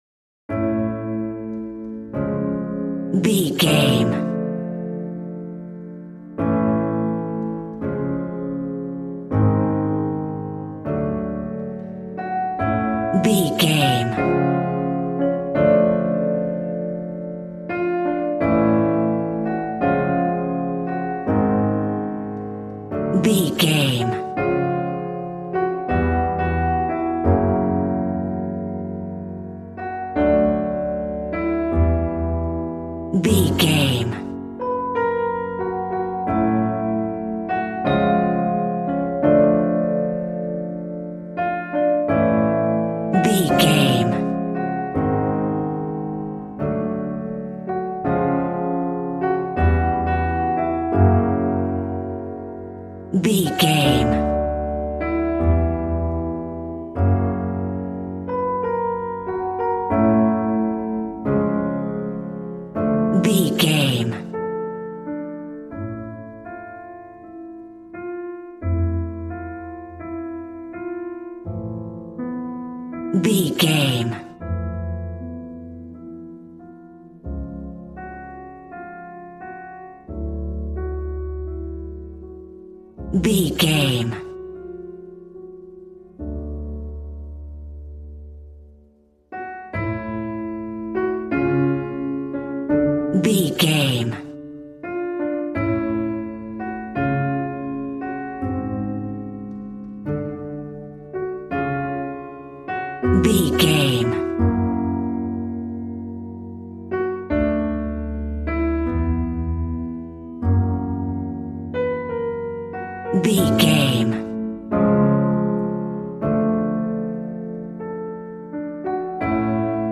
Regal and romantic, a classy piece of classical music.
Aeolian/Minor
G♭
regal
strings
violin